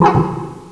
Cri de Ponchien dans Pokémon Noir et Blanc.